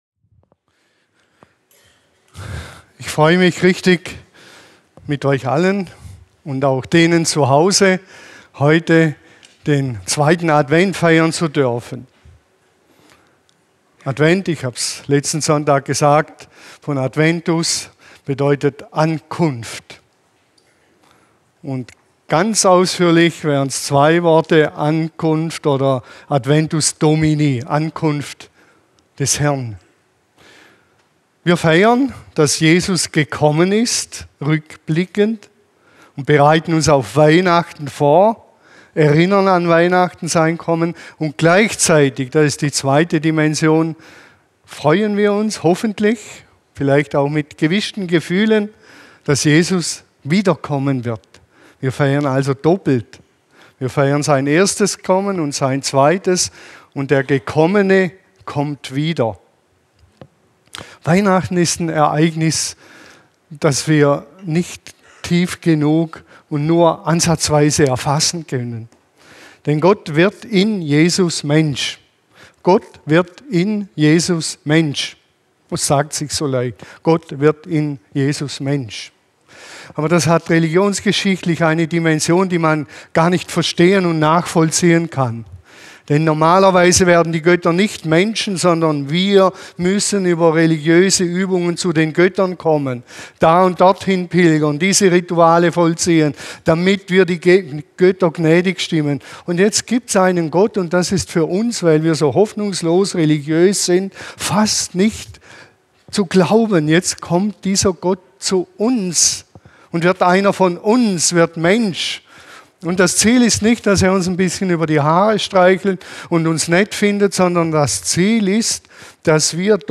Zeichen deuten – Bereit für das Kommen Jesu? ~ LIWI-Predigten Podcast